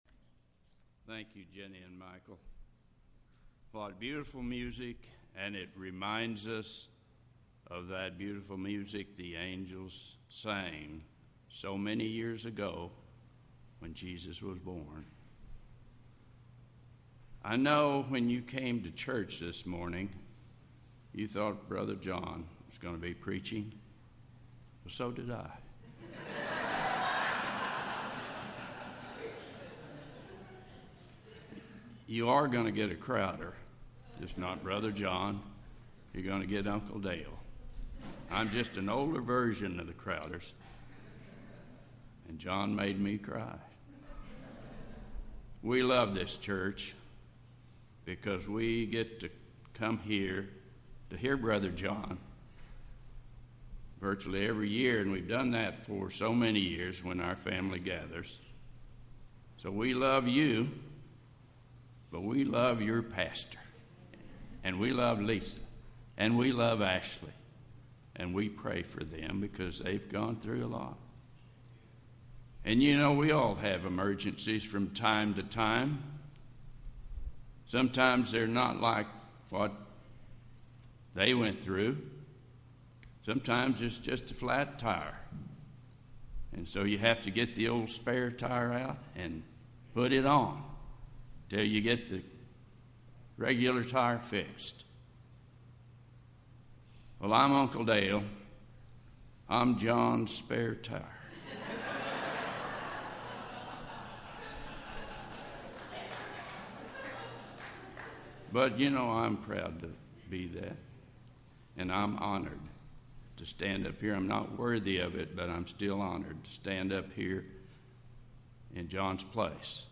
Christmas Message